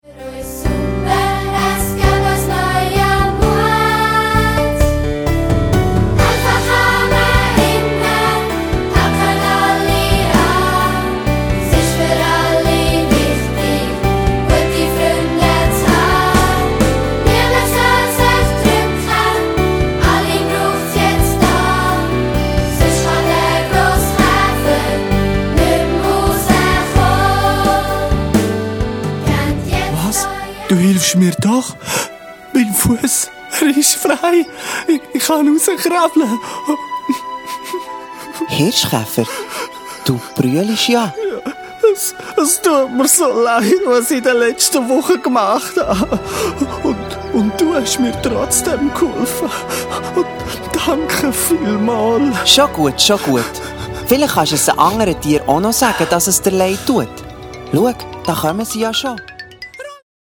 Minimusical für Grundschule und 1./2. Klasse